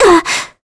Scarlet-Vox_Damage_02_kr.wav